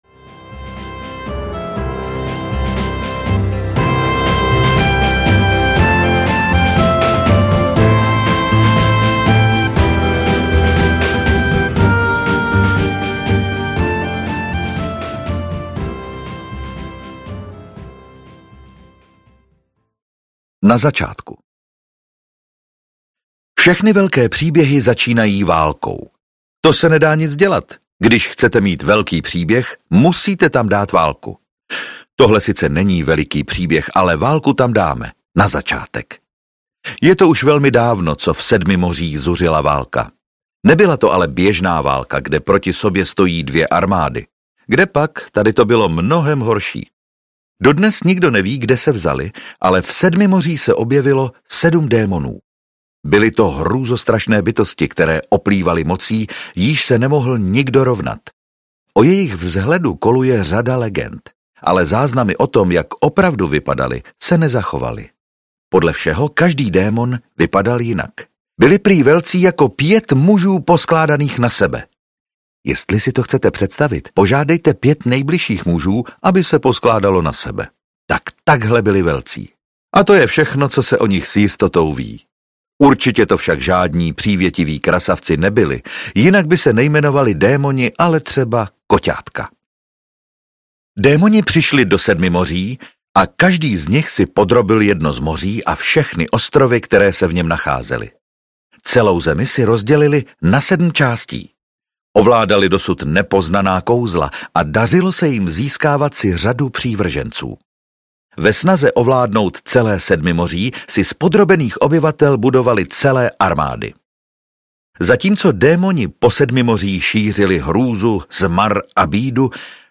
Lapuťák a kapitán Adorabl audiokniha
Ukázka z knihy